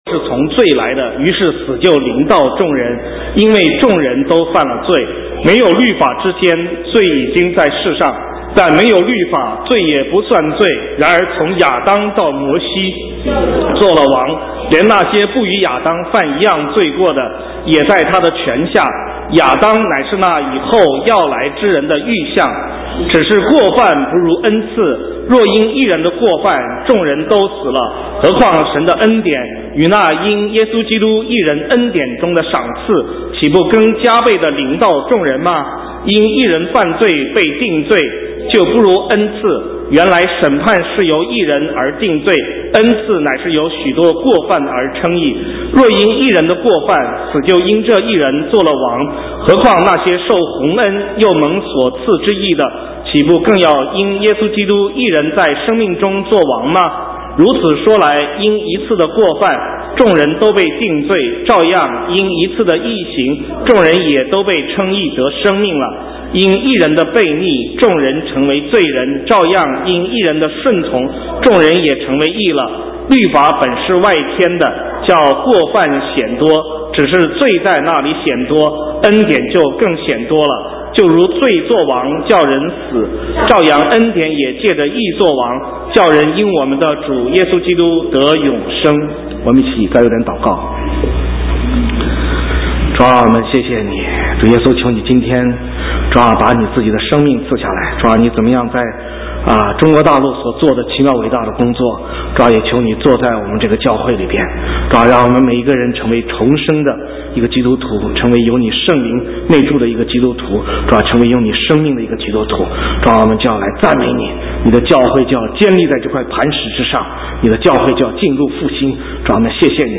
神州宣教--讲道录音 浏览：重生的生命 (2012-03-25)